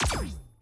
Alien weapon/blast sounds
22khz mono already.
disruptor2_180.wav